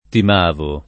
Timavo [ tim # vo ] top. m.